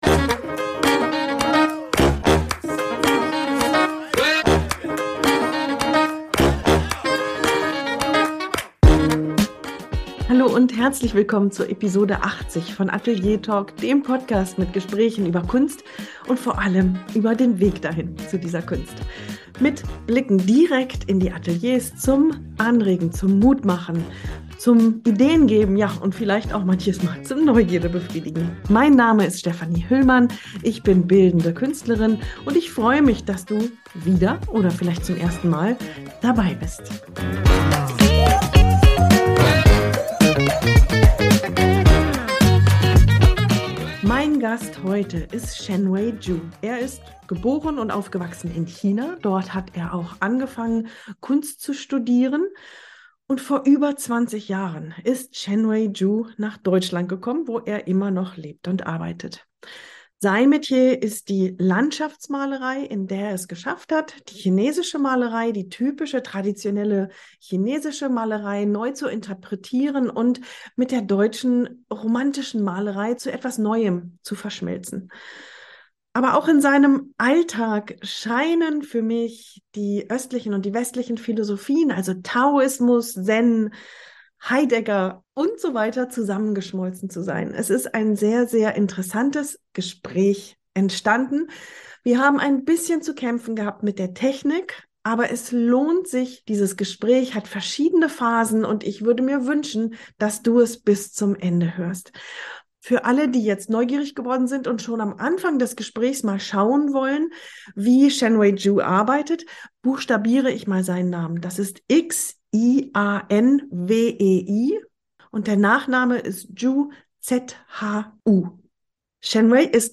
Gespräch mit einem Wandler zwischen Zeiten und Kulturen